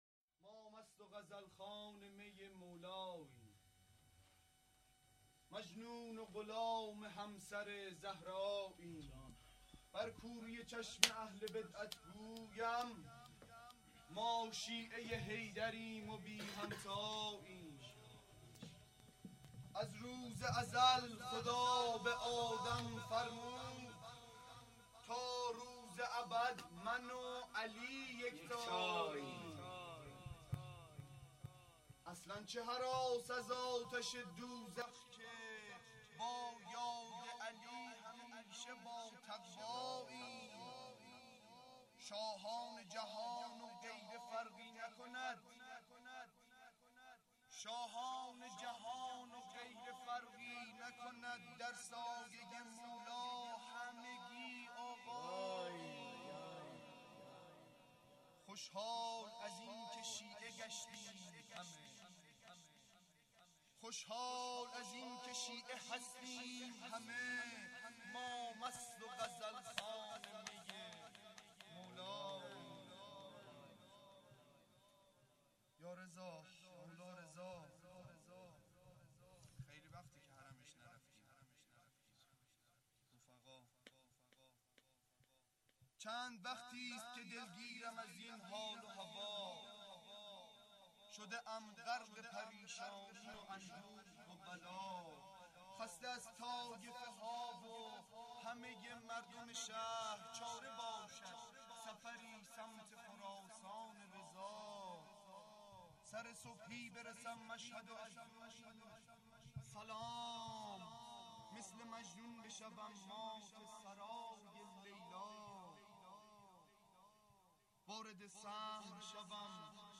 شعر خوانی
جشن ولادت حضرت زهرا(س)